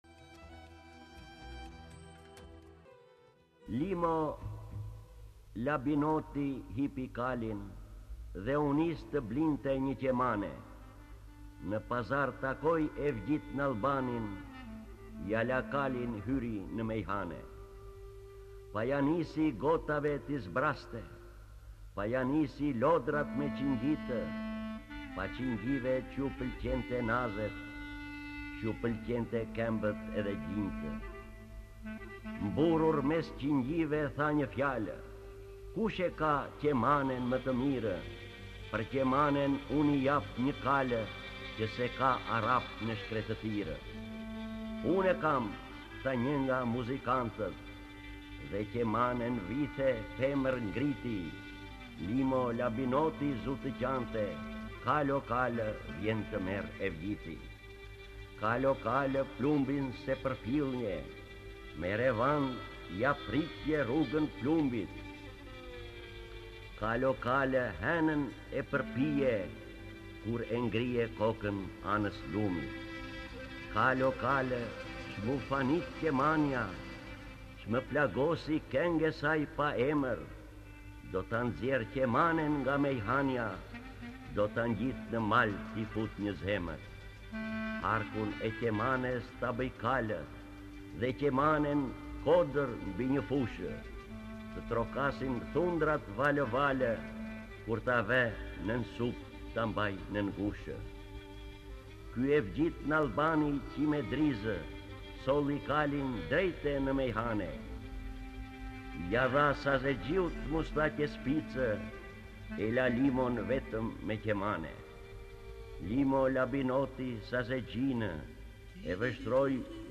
D. AGOLLI - KALI Lexuar nga D. Agolli KTHEHU...